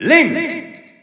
Announcer pronouncing Link in French.
Link_French_Announcer_SSBU.wav